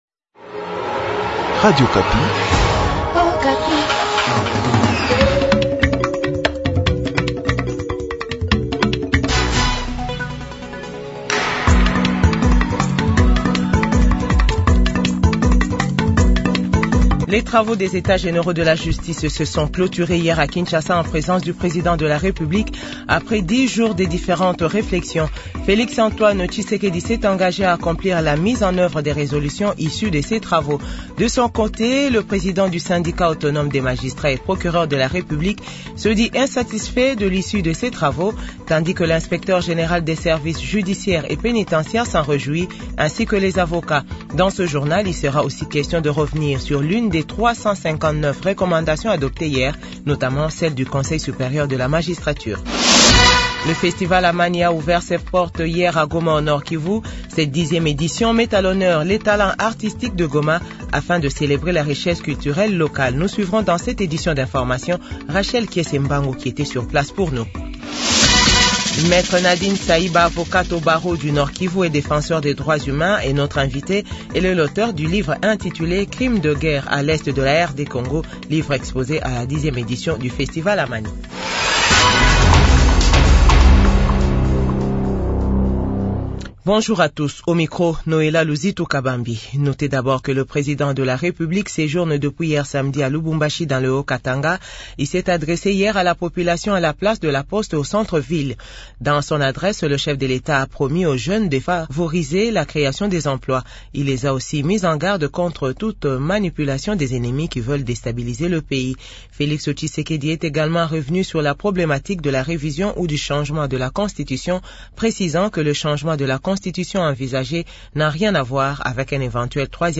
JOURNAL FRANCAIS DE 8H00